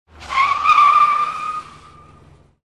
Звуки тормоза автомобиля
На этой странице собрана коллекция звуков тормозов автомобилей: от резкого визга шин до плавного скрежета.
Шум резины при торможении машины на асфальте